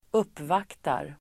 Uttal: [²'up:vak:tar]